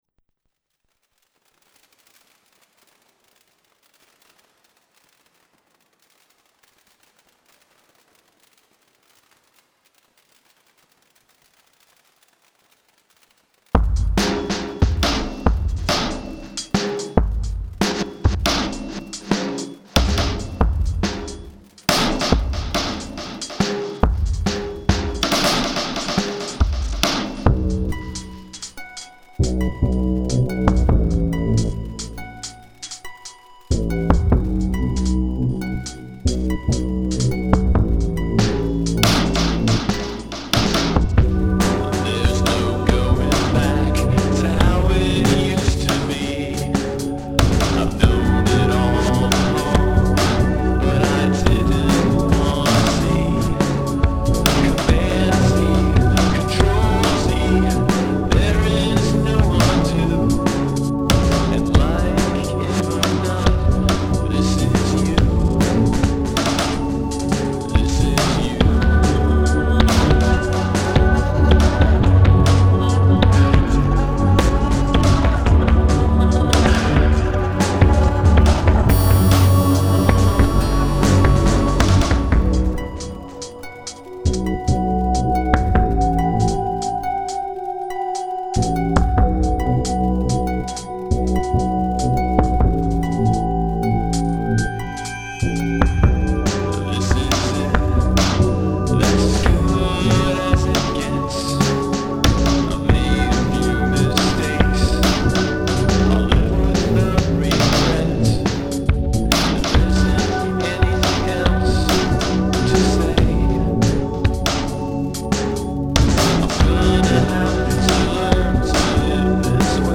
An homage/rip-off of everything on Burial's "Untrue" album.